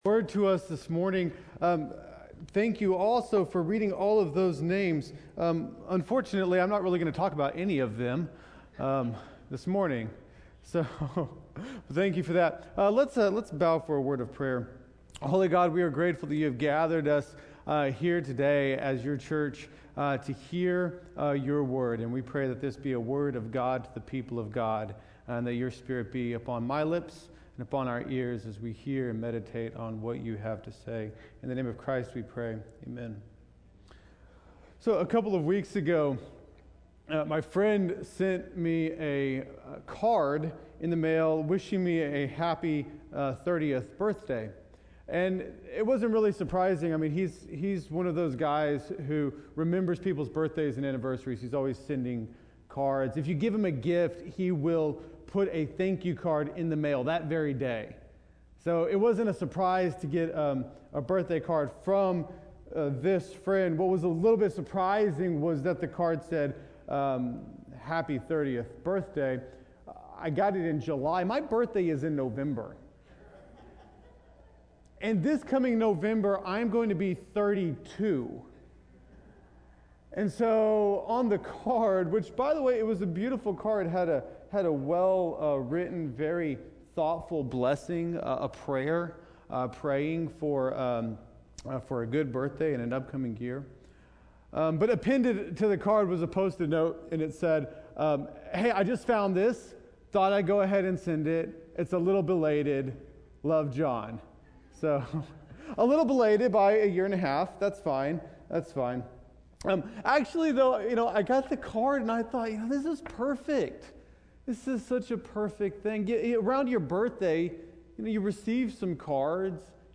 Weekly Sermon Audio “Dig-In”